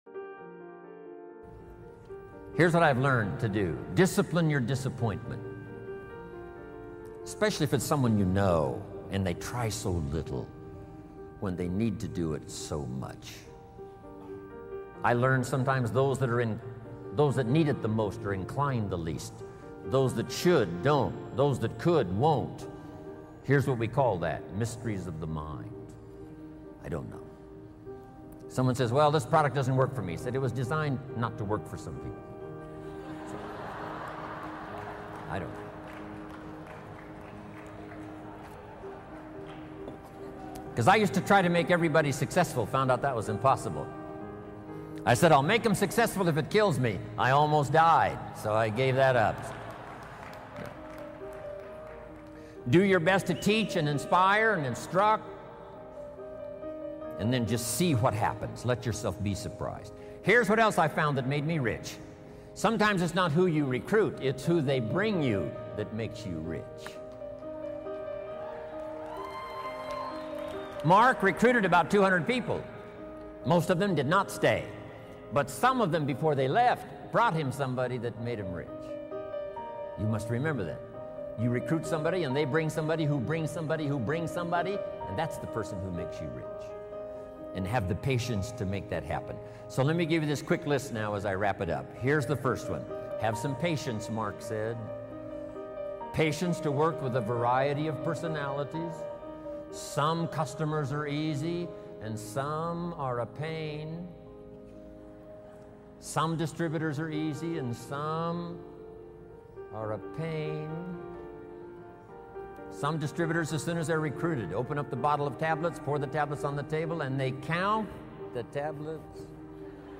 Jim Rohn - Understanding the mysteries of the mind motivational speech